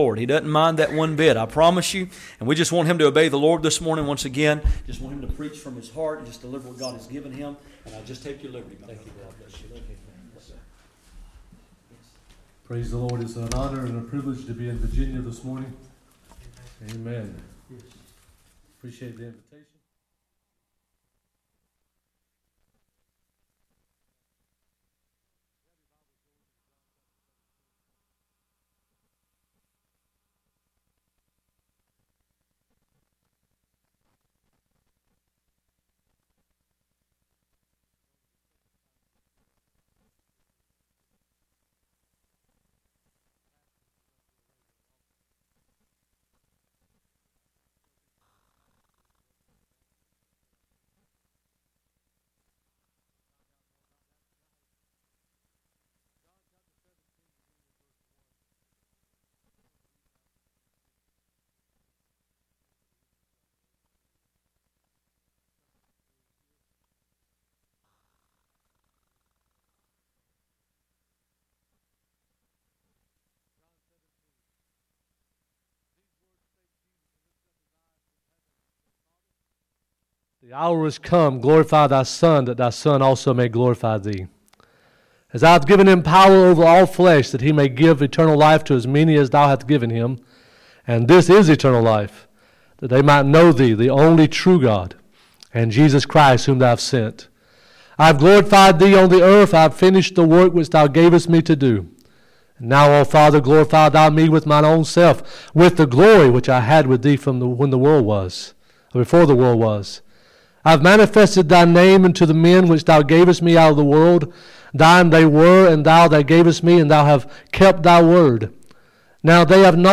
Matthew 16:13-20 Service Type: Sunday Morning %todo_render% « Questions